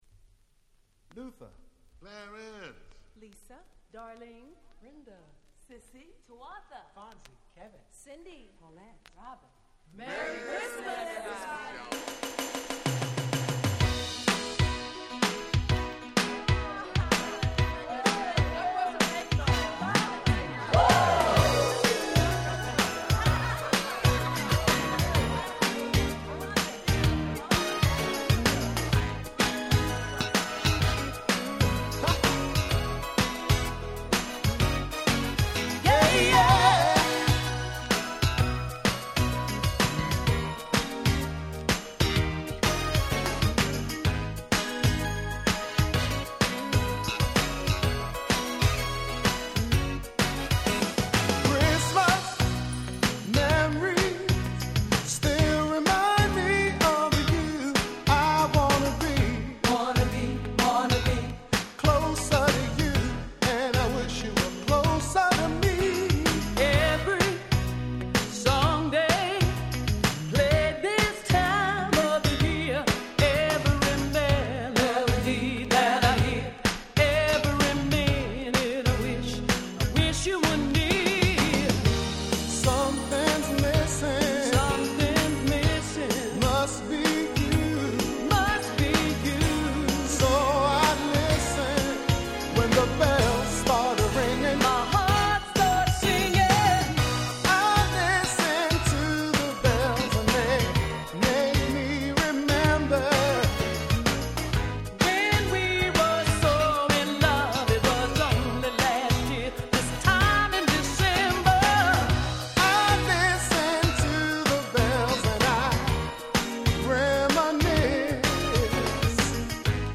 タイトル通り全曲クリスマスソング！！
音質もバッチリ！